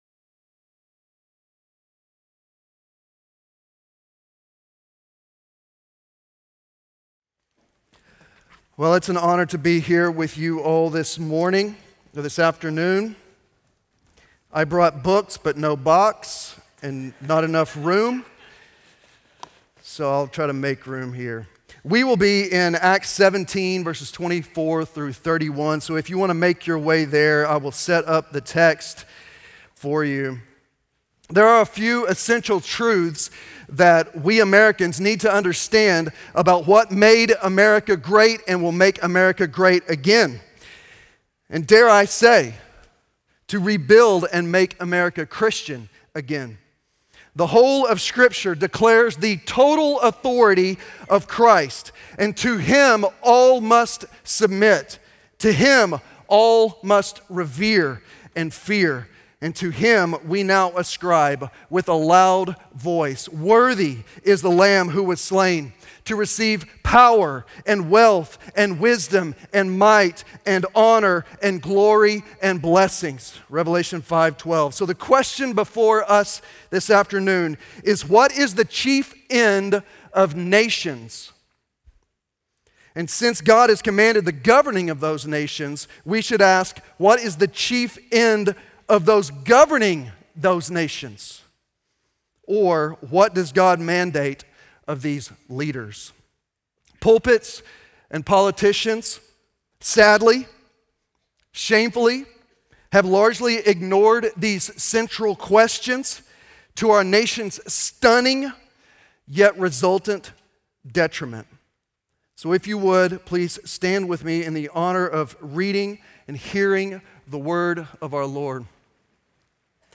This presentation was given by Pasty Dusty Deevers on January 23, 2026 at the "Make Disciples" 2026 National Founders Conference in Fort Myers, Florida.